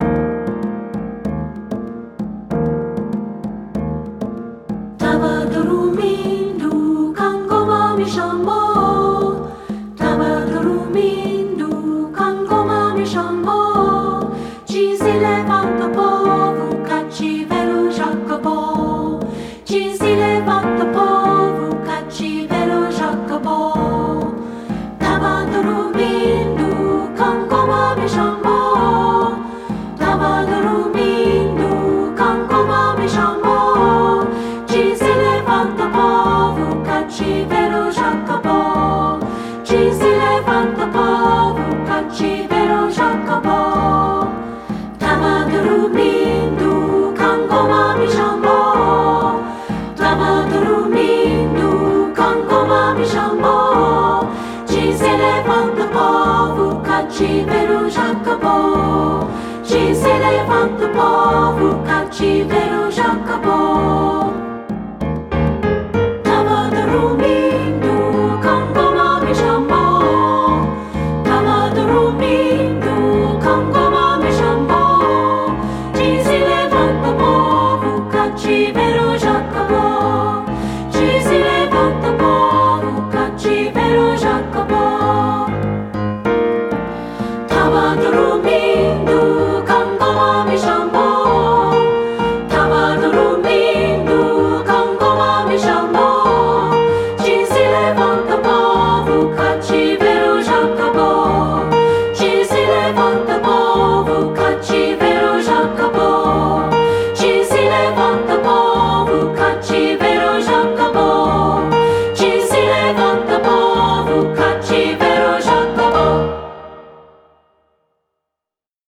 • Descant
• Percussion
• Piano
Studio Recording
Ensemble: Treble Chorus
Key: E major, A major
Tempo: Joyfully (q = 96)
Accompanied: Accompanied Chorus